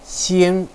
xing1.wav